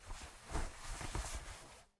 Cloth Rustling
Soft fabric rustling as clothing moves with natural texture and gentle friction
cloth-rustling.mp3